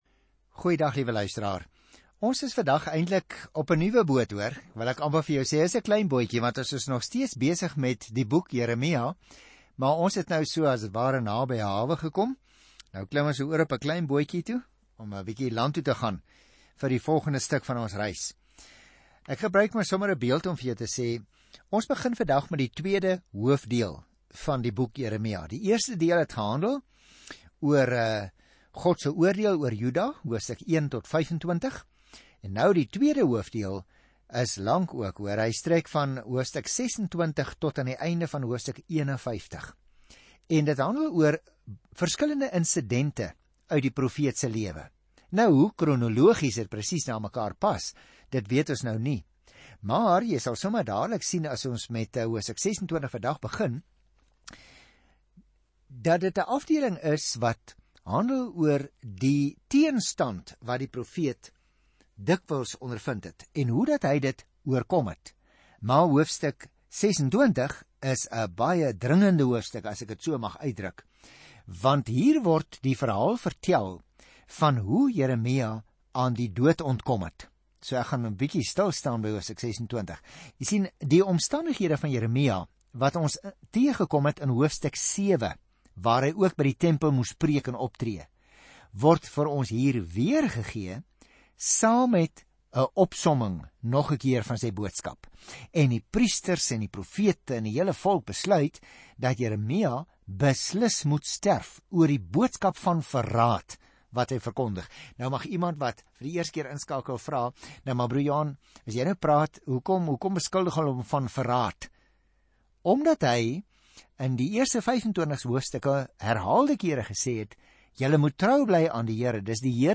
Dit is ’n daaglikse radioprogram van 30 minute wat die luisteraar sistematies deur die hele Bybel neem.